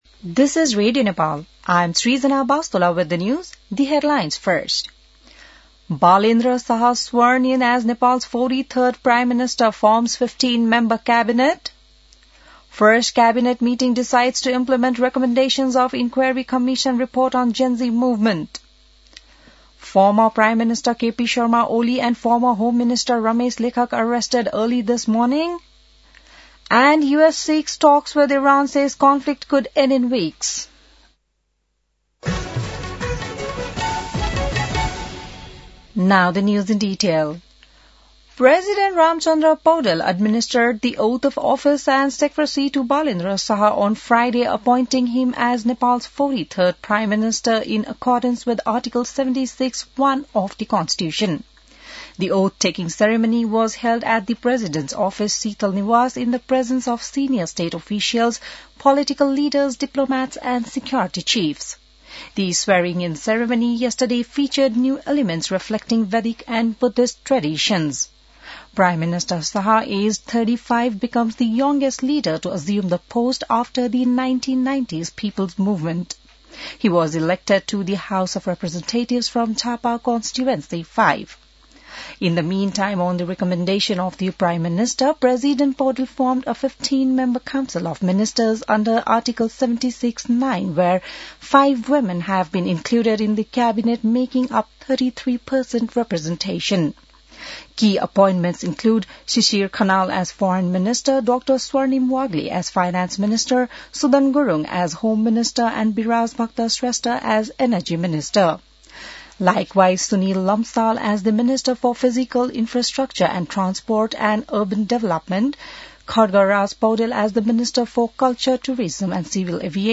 बिहान ८ बजेको अङ्ग्रेजी समाचार : १४ चैत , २०८२